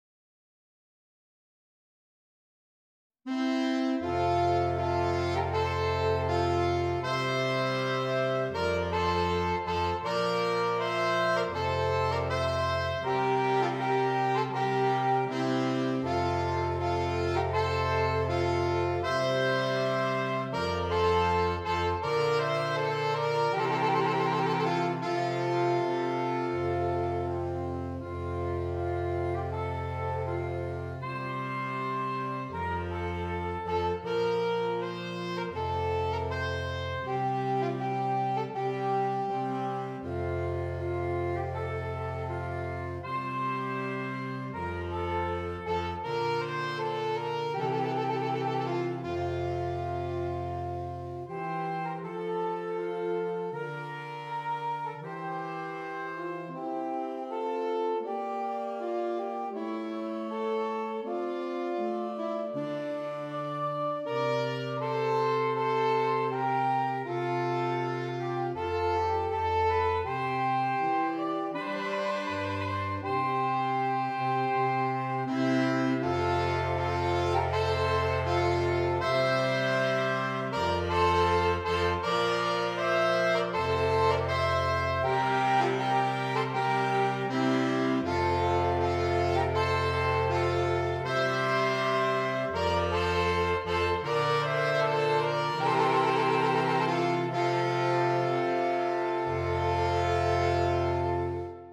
Saxophone Quartet (SATB or AATB)